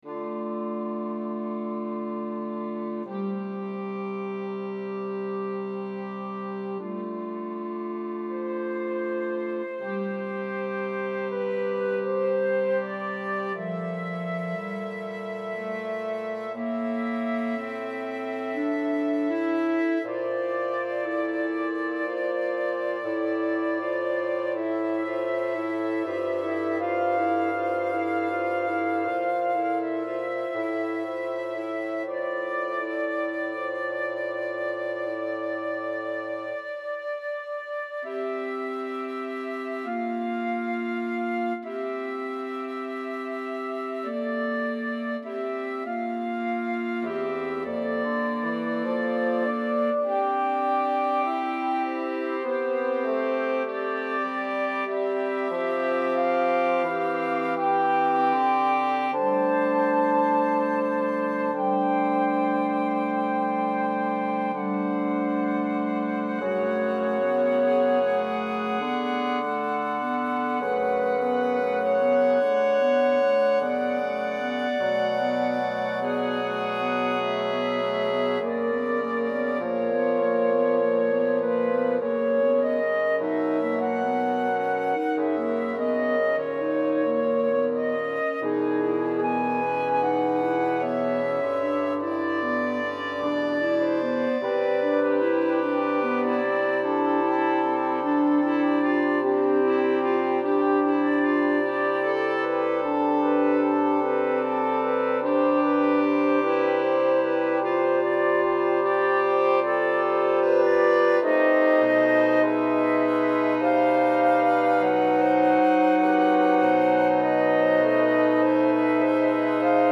(1987) Chorus